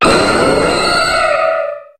Cri de Méga-Ptéra dans Pokémon HOME.
Cri_0142_Méga_HOME.ogg